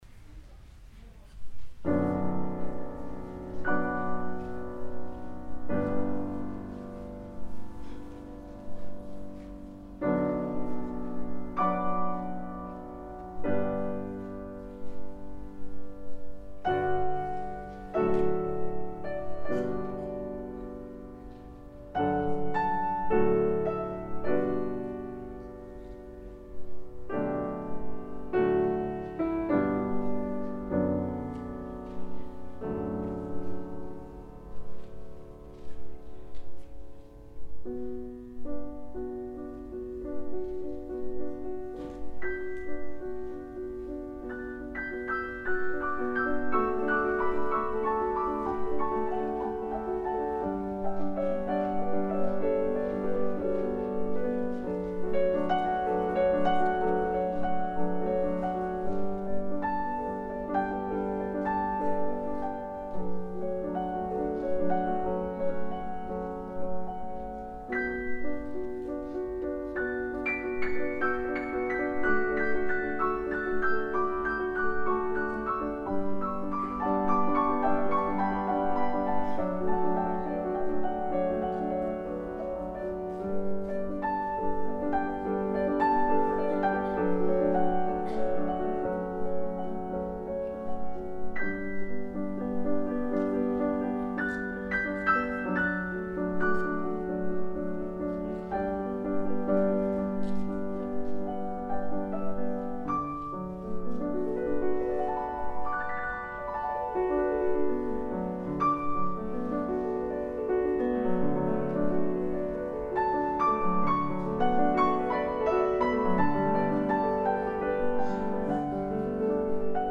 solo piano
Varner Hall, Oakland University https